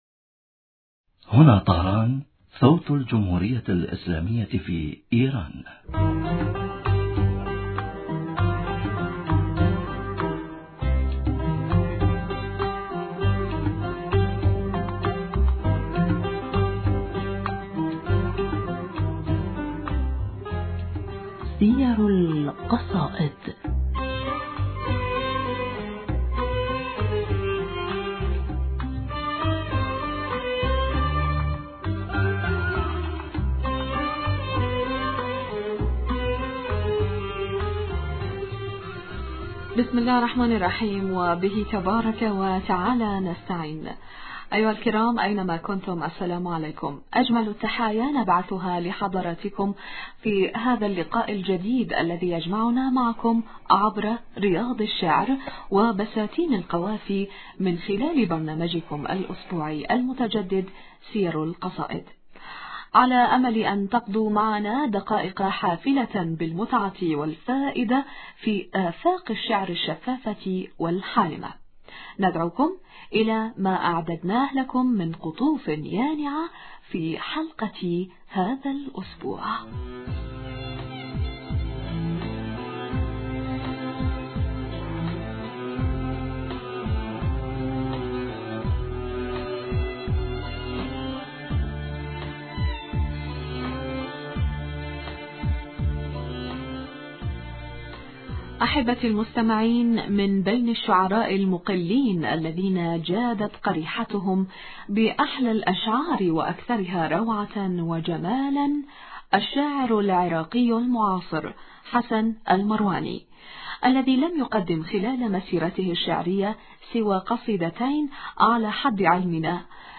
المحاورة